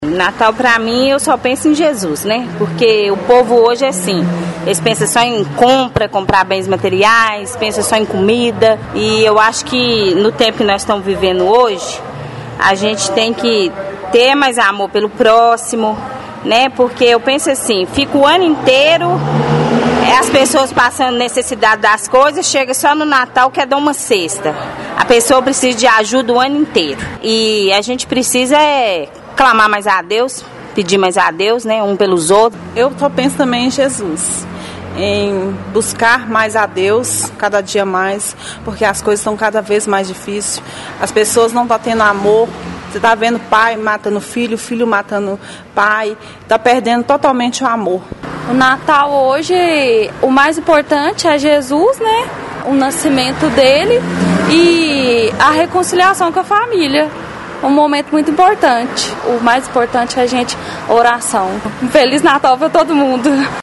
O JM foi ás ruas pra saber como os paraminenses percebem a festividade do Natal, e entre as palavras que mais apareceram para definir a data estão ‘fé’ e ‘família’.
Paraminenses